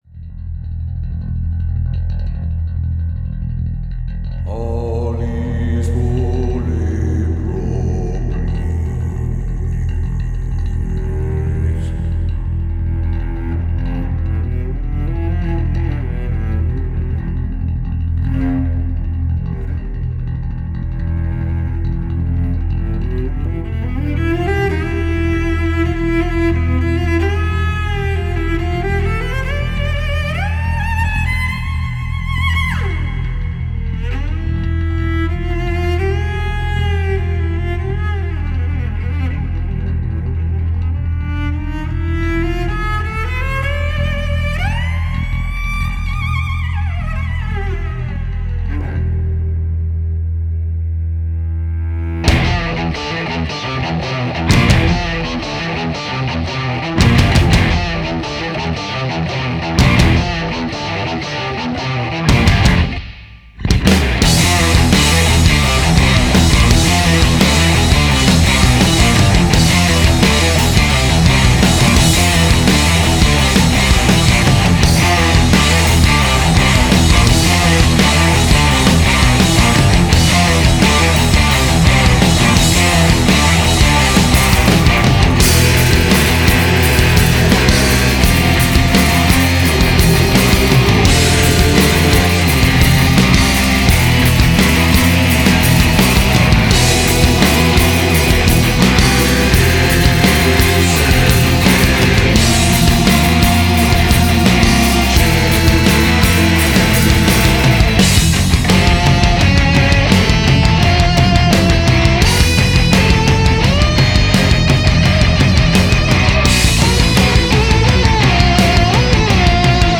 Nu Metal